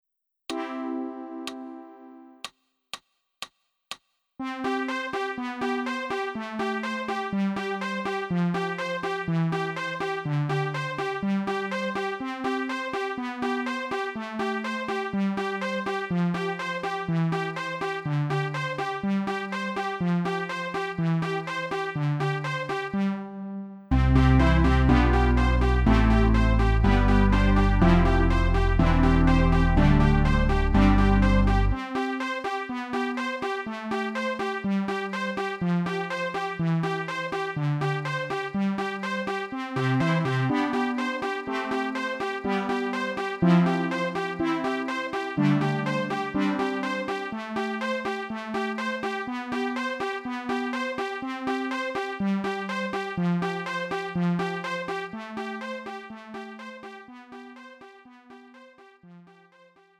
음정 원키 3:08
장르 pop 구분 Lite MR